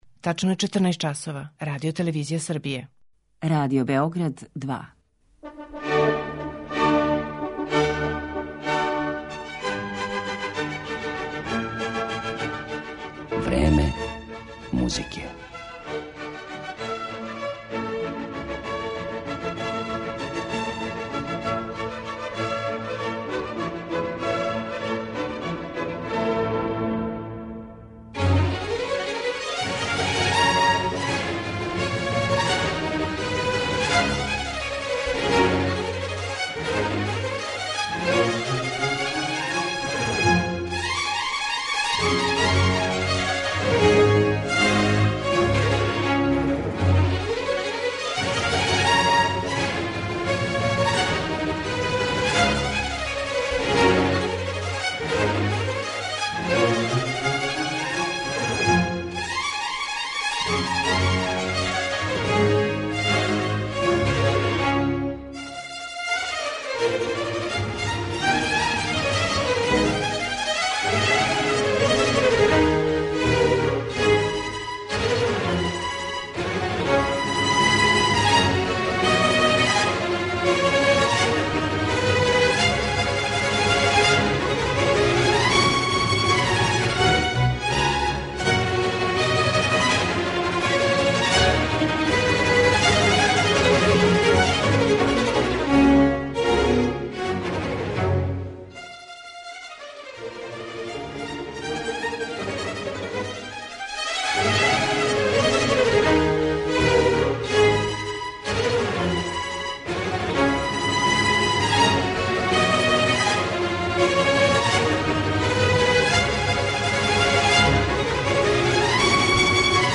У данашњем Времену музике представићемо га особеним интерпретацијама дела Жан-Филипа Рамоа, Волфганга Амадеуса Моцарта, Петра Чајковског, Игора Стравинског и Густава Малера.